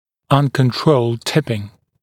[ʌnkən’trəuld ‘tɪpɪŋ][анкэн’троулд ‘типин]неконтролируемый наклон